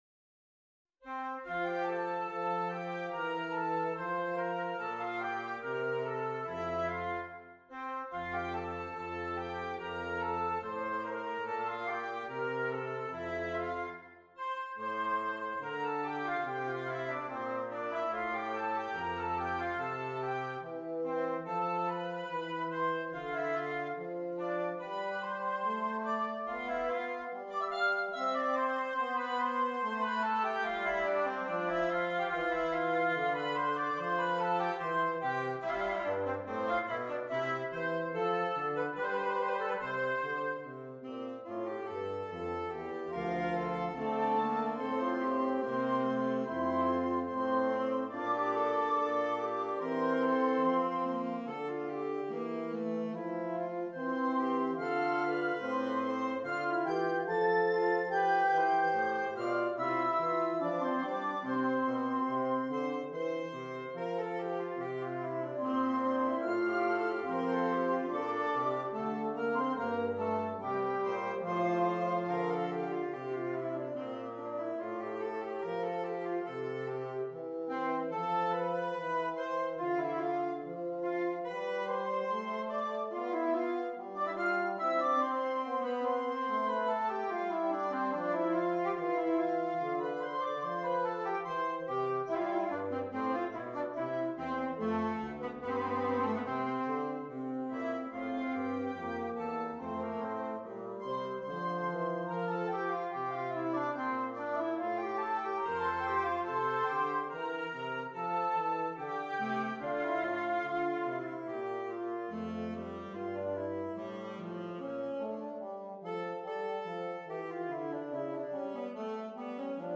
Interchangeable Woodwind Ensemble
PART 1 - Flute, Oboe, Clarinet
PART 4 - Clarinet, Alto Saxophone, Tenor Saxophone, F Horn
PART 5 - Bass Clarinet, Bassoon, Baritone Saxophone